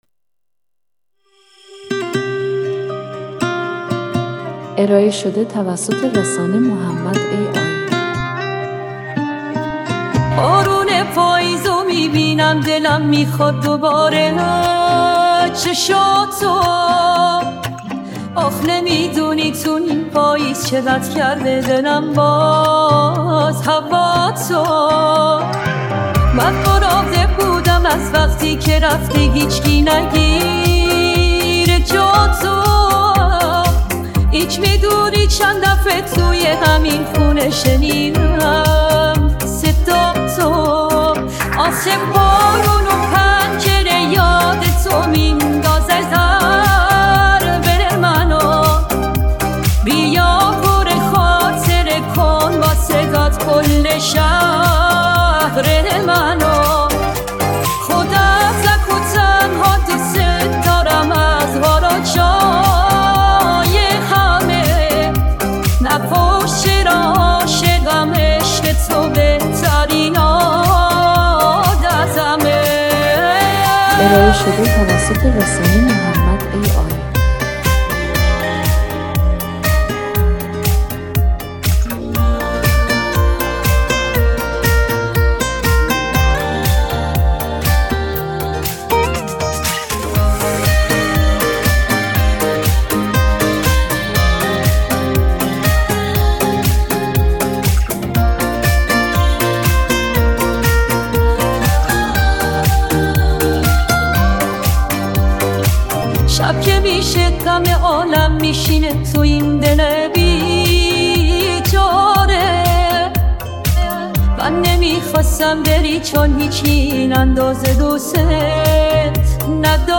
این آهنگ با هوش مصنوعی ساخته شده است